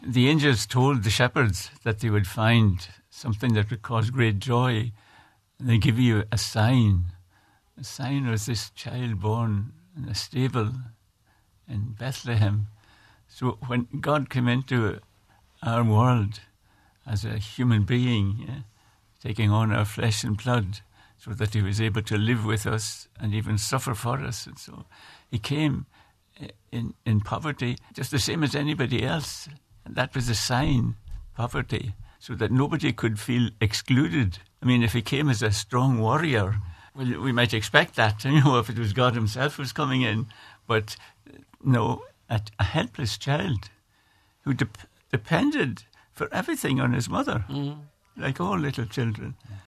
Bishops stress the true meaning of the season in special Christmas Eve broadcast
On a special Nine til Noon Show this morning, Bishop Emeritus Philip Boyce, the retired Bishop of Raphoe said Christmas is rooted in poverty and inclusiveness………..